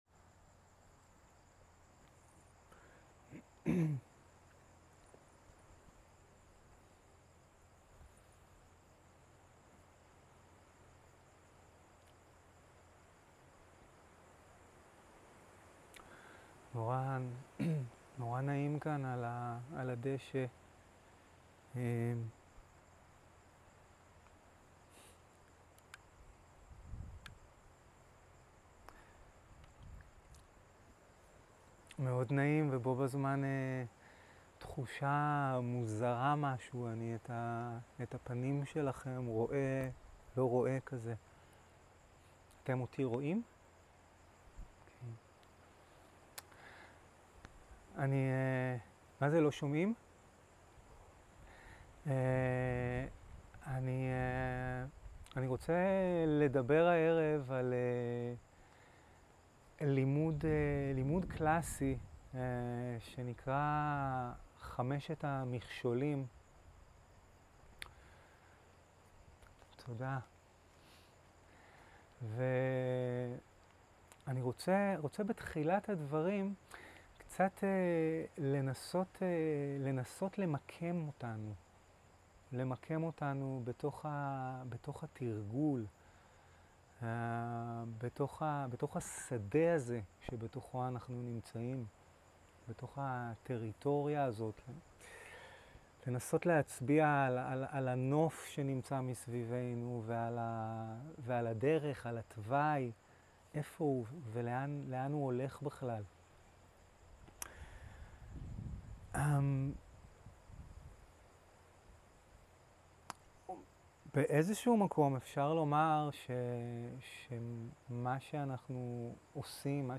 סוג ההקלטה: שיחות דהרמה
איכות ההקלטה: איכות גבוהה